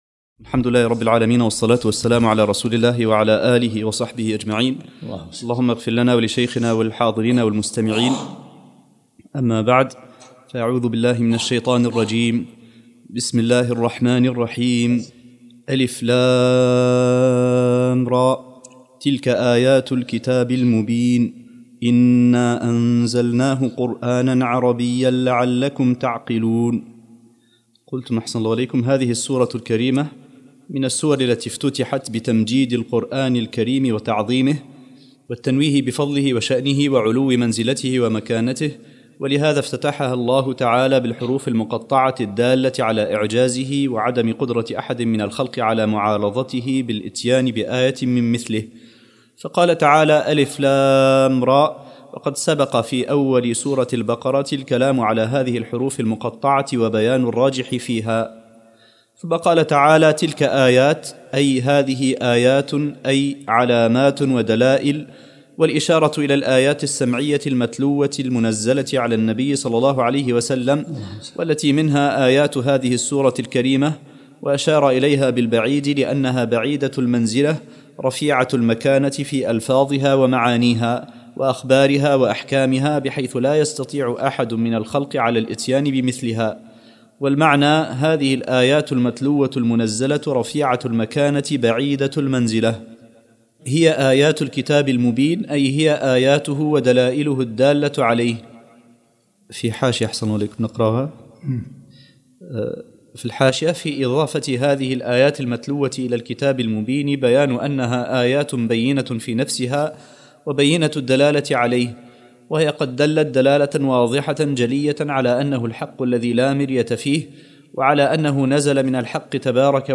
الدرس الرابع عشرمن سورة يوسف